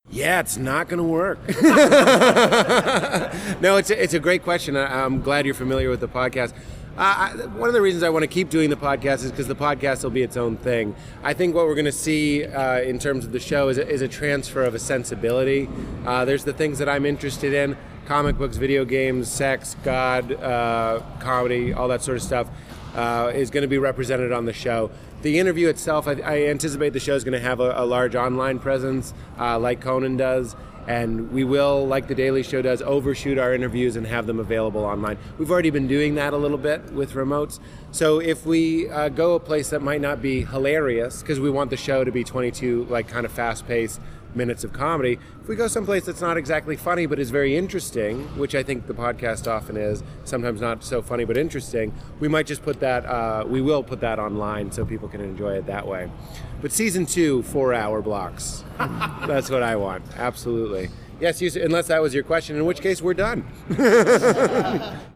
While audio of the Q&A was recorded, most of the questions coming in were too far from the microphone to be heard well, so you can read each question below and then hear the response given by the panel.